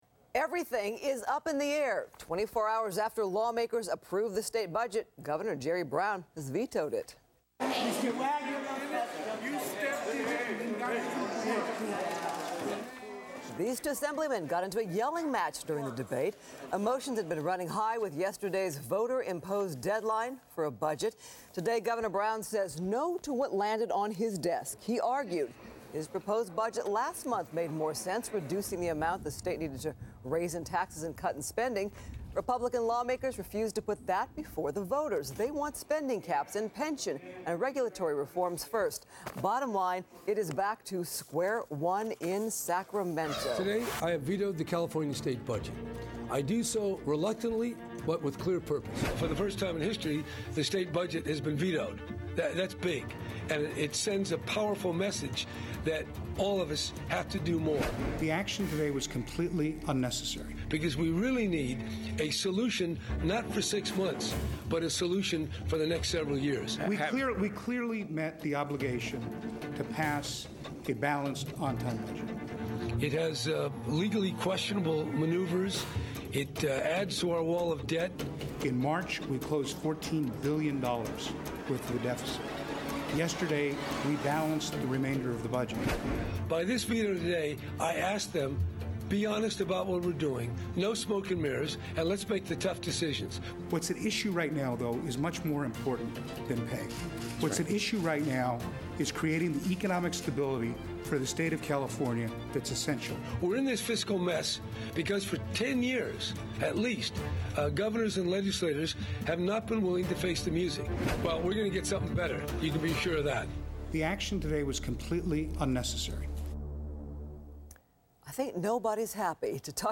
talks to FOX 11 Los Angeles about Governor Jerry Brown's decision to veto the California budget passed through the assembly at the last minute.